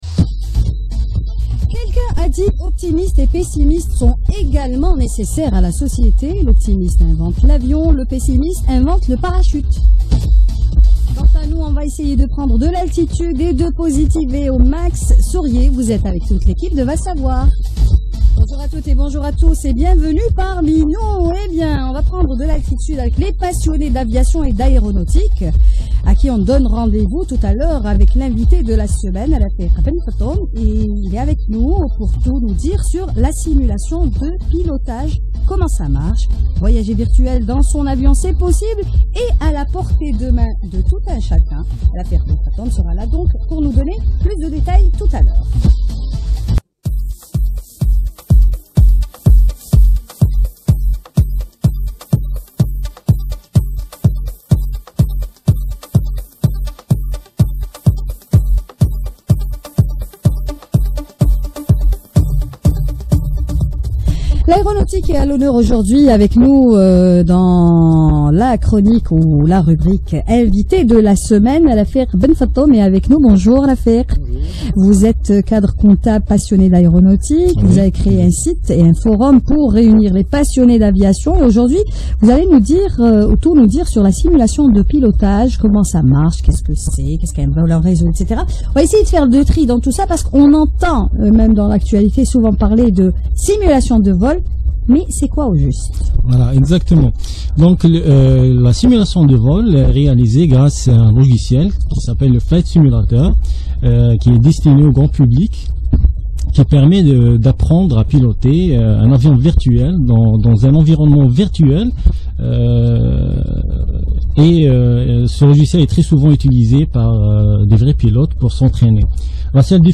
interview.mp3